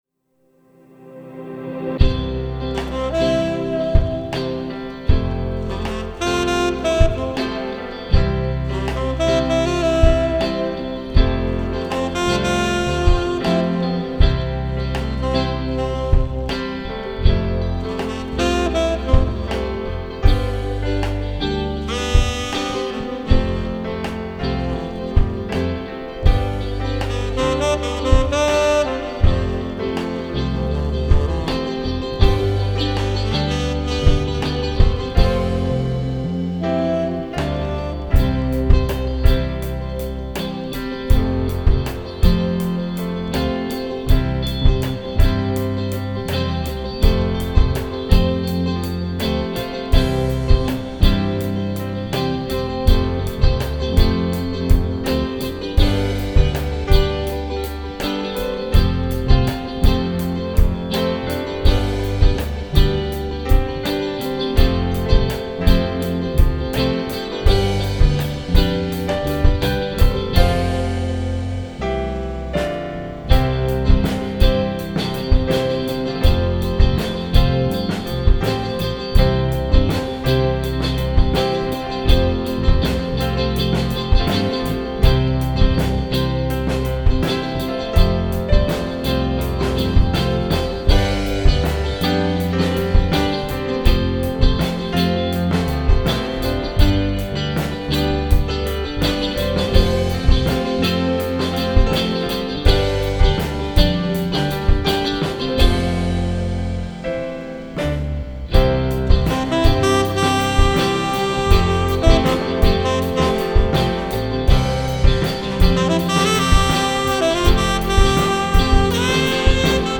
Trummor
Saxomofon
Piano